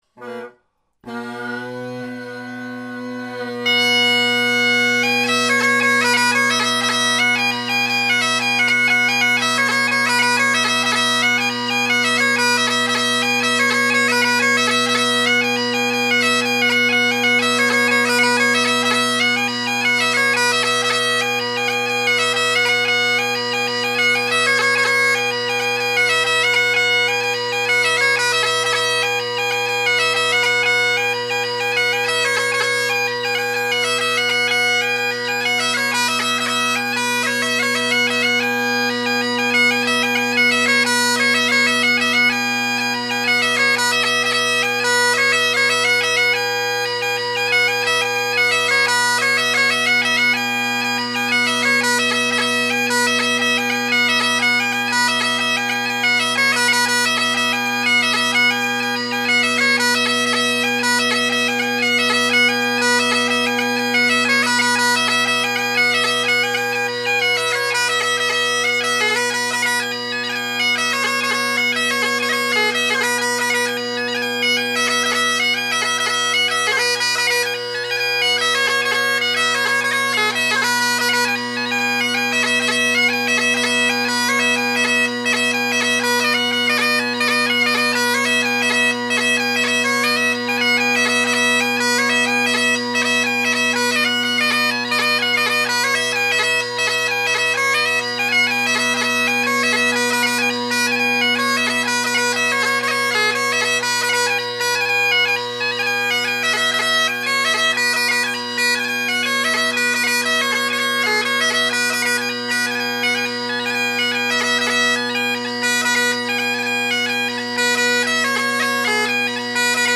Great Highland Bagpipe Solo
Blowing in a Husk chanter reed for this pipe so steadiness is a bit of an issue as is tuning with this set of recordings.